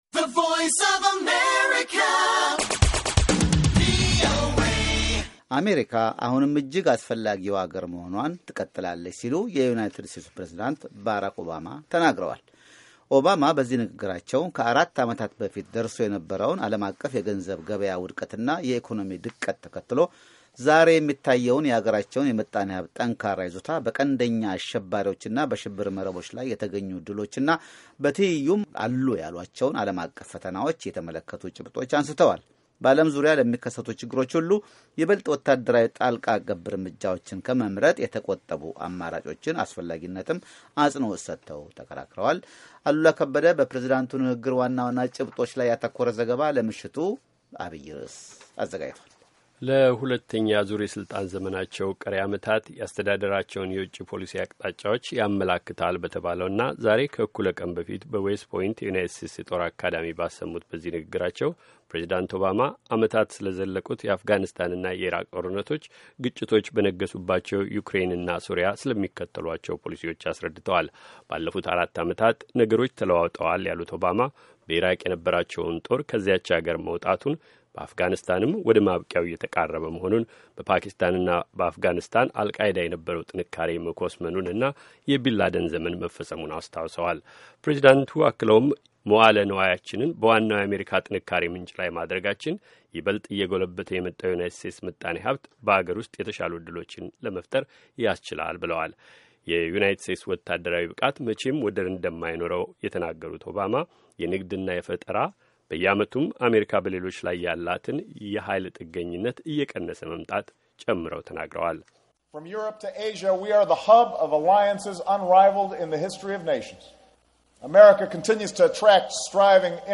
የዩናይትድ ስቴትስ ፕሬዚዳንት ባራክ ኦባማ የዌስት ፖይንት ወታደራዊ አካዳሚ ካዴቶችን በመረቁበት ወቅት ንግግር ሲያደርጉ፤ ግንቦት 20/2006ዓ.ም